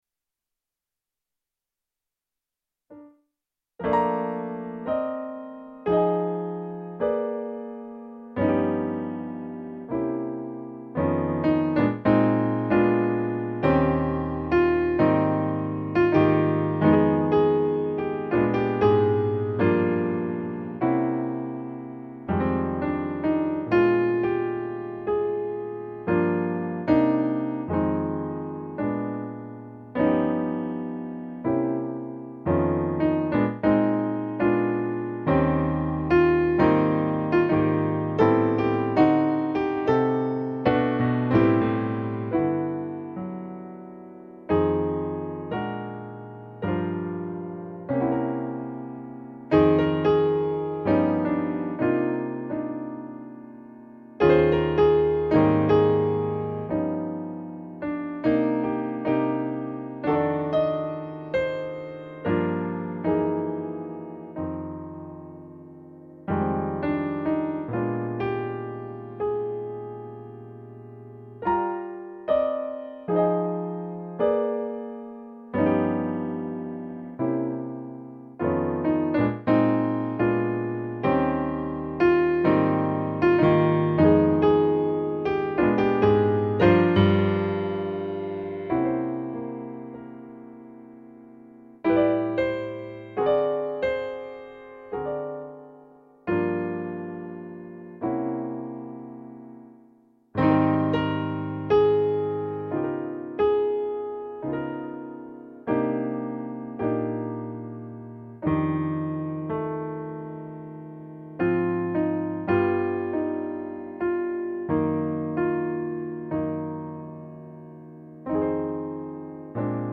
By chance, I hit the record button on the Clavinova.